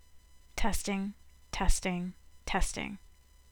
high-pitched mosquito whine/hum in all headset recordings
I’m using a little consumer-grade Sennheiser PC 8 headset on Mac 10.6.8 and every recording I take has a light (but audible) mosquito hum.